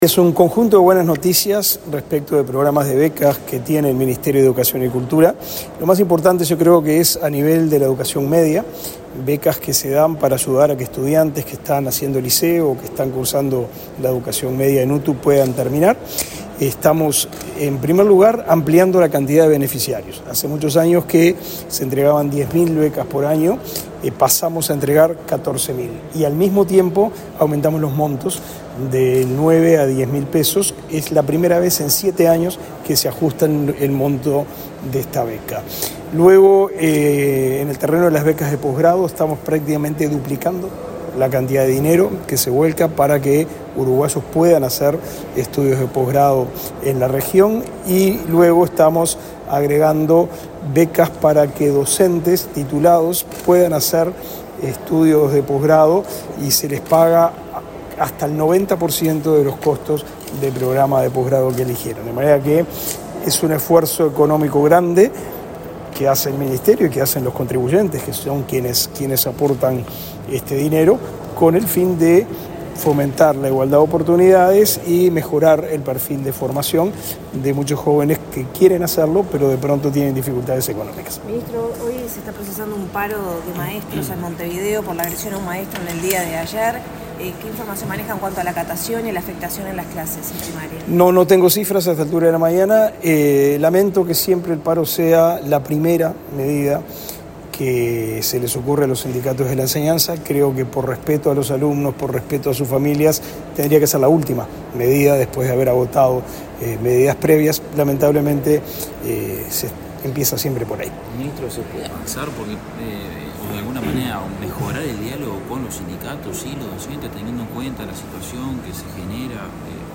El ministro de Educación y Cultura, Pablo da Silveira, fue entrevistado para medios periodísticos, luego de participar de una conferencia de prensa